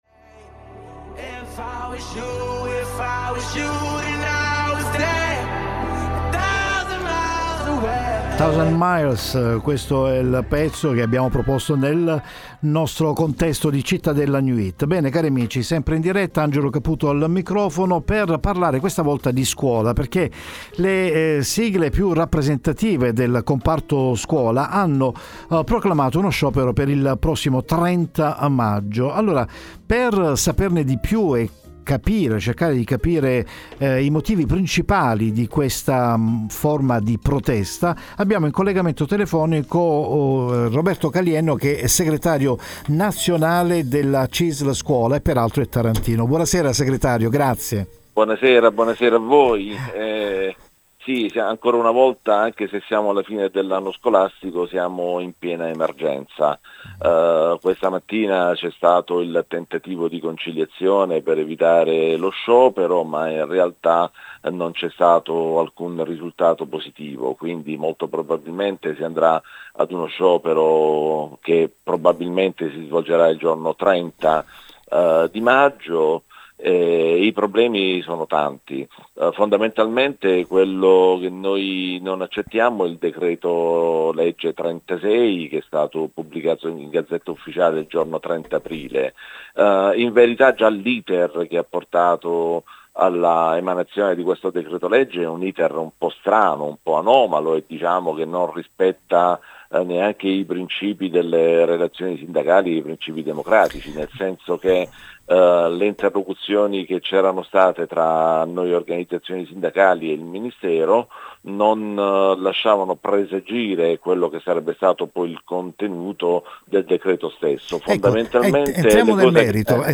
- L'intervista